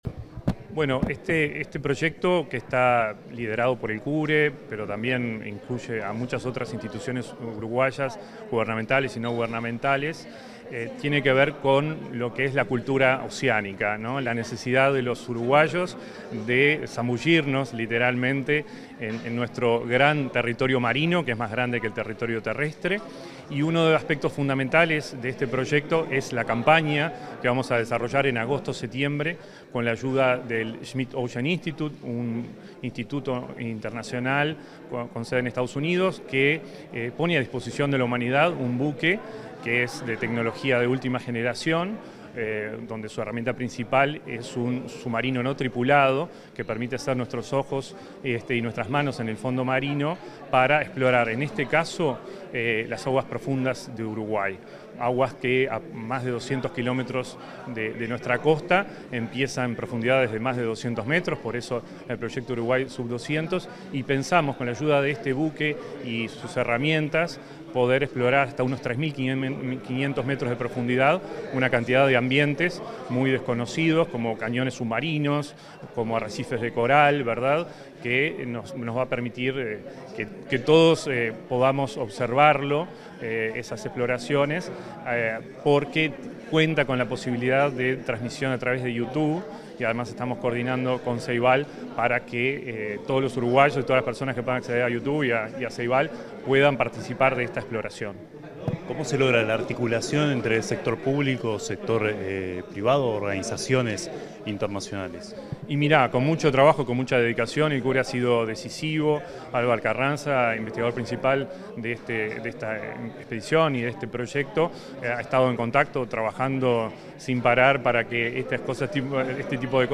Declaraciones
realizó declaraciones en el Centro Universitario Regional del Este de la Universidad de la República, en Maldonado.